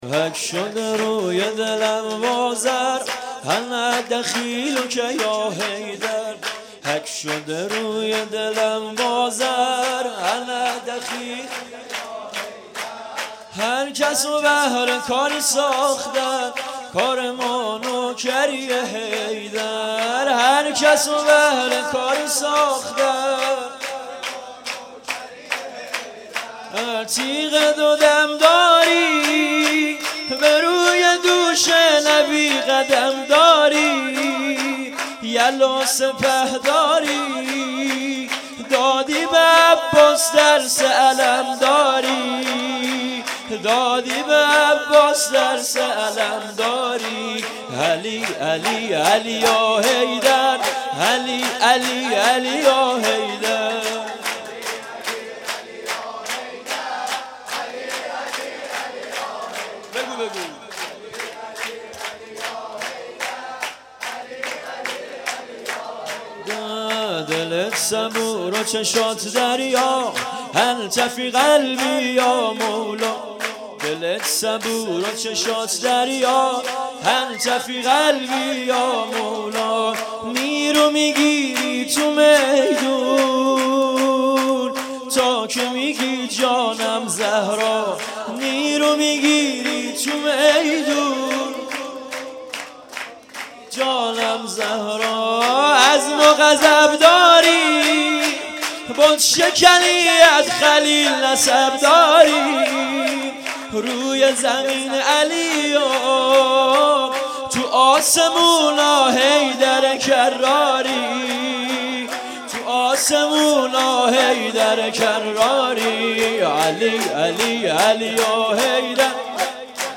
جشن ولادت امام هادی علیه السلام